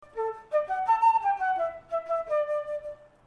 Tune